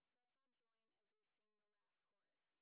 sp30_white_snr30.wav